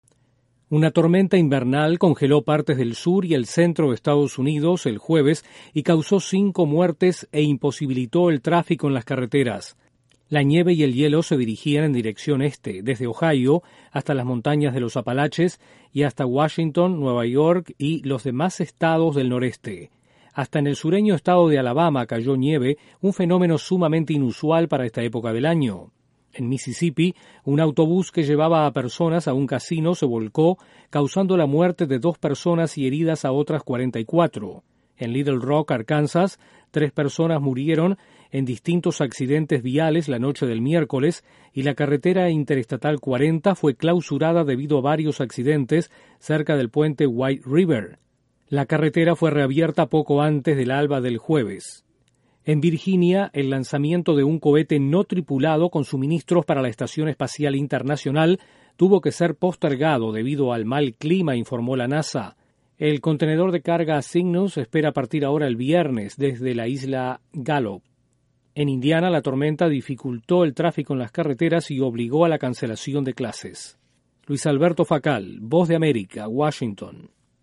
Una tormenta invernal en Estados Unidos causa cinco muertos en el sur del país. Desde la Voz de América en Washington informa